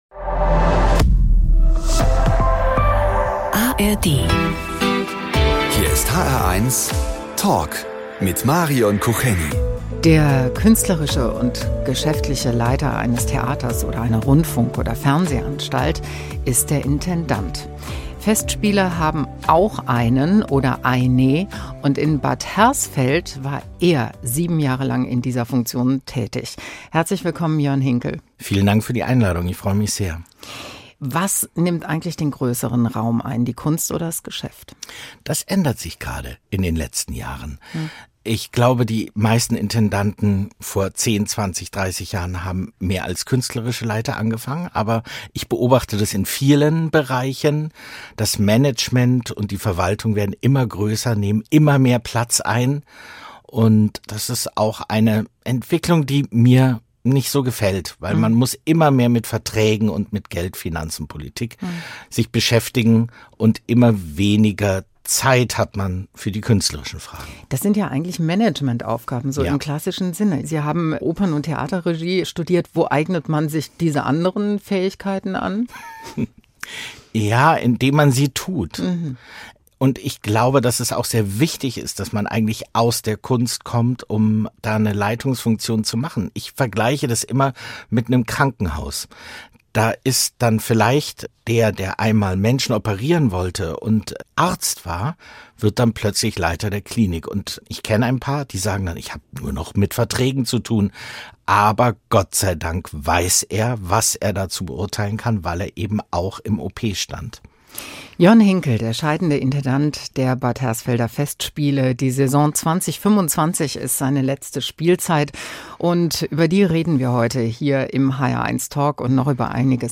Überraschende Einblicke und spannende Ansichten: Die hr1-Moderatoren im sehr persönlichen Gespräch mit Prominenten.